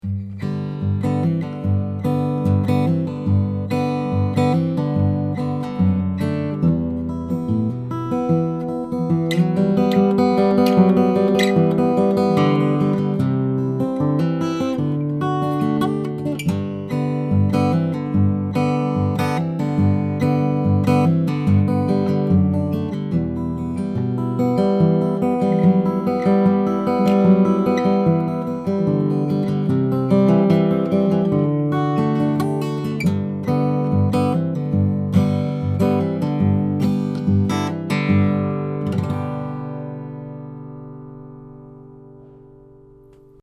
* Polar Pattern: Cardioid
Here are 39 quick, 1-take MP3s of these mics into a Presonus ADL 600 preamp with a Rosetta 200 A/D converter. This is straight signal with no additional EQ or effects:
SANTA CRUZ OM/PW AC. GUITAR:
Dfly2OMPWFingerpicking.mp3